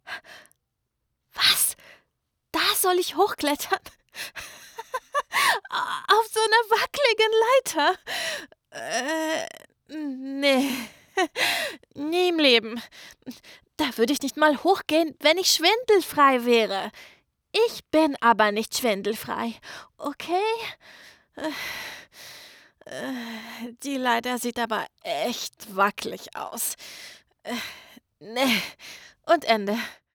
Sprechprobe
Stimmalter: ca. 13-33 Jahre
Stimmfarbe: klar, weich, warm, energisch, zart, verspielt, frech, überdreht, freundlich, begeistert.
Aufnahmezimmer mit geschlossener Sprechkabine
Freude